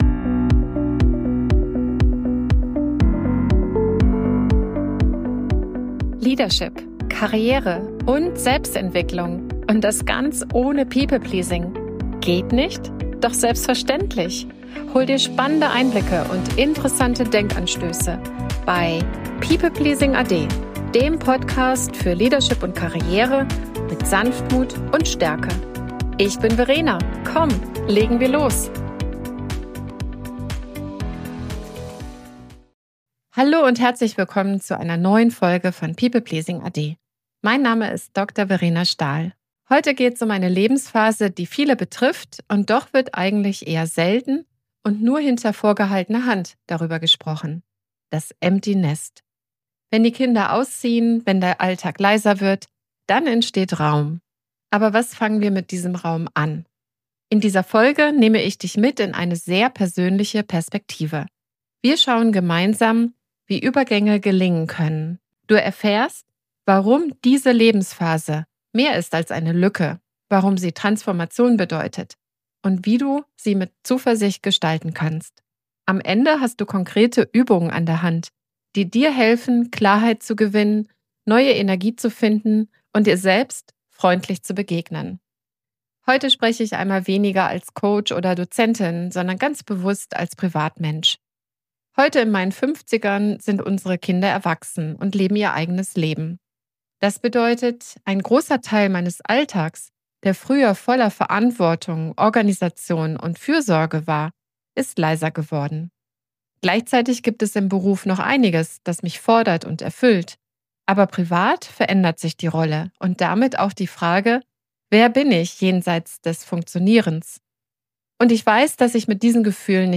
In dieser Solo-Folge spreche ich darüber, wie wir solche Übergangsphasen mei...